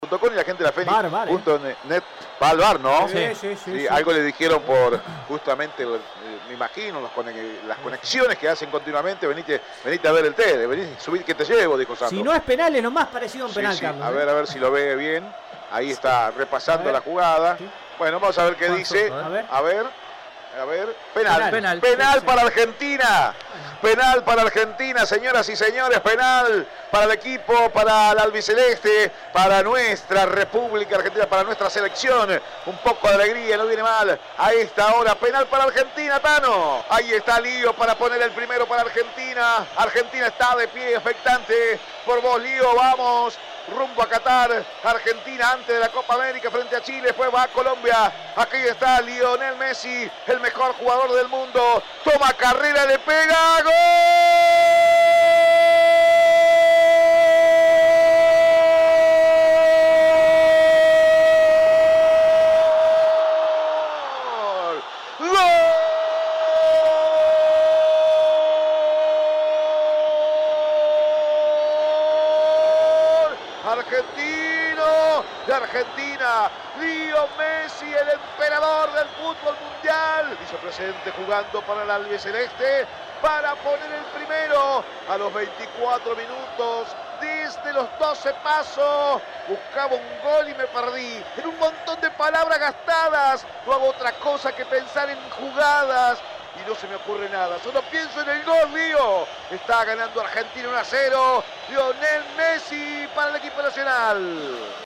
01-GOL-DE-MESSI-ARGENTINA-EDITADO.mp3